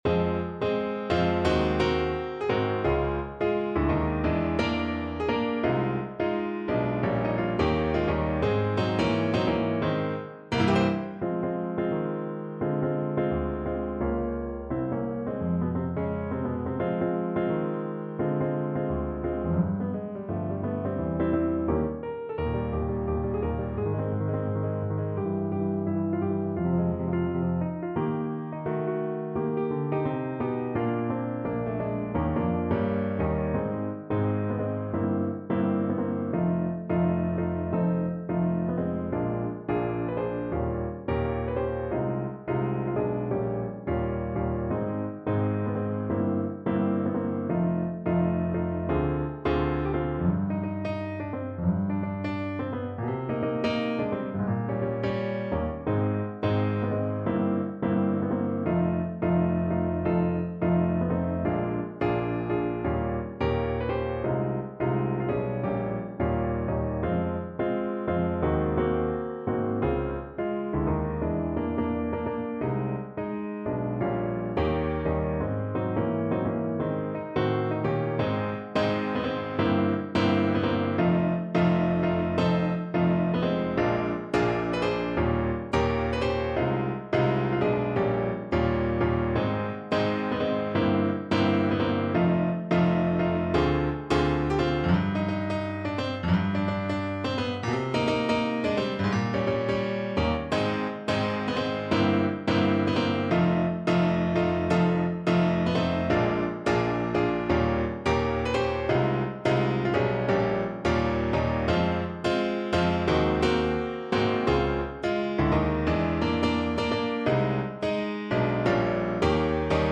Alto Saxophone
2/2 (View more 2/2 Music)
Animato =86
Jazz (View more Jazz Saxophone Music)